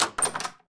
sounds_door_open.ogg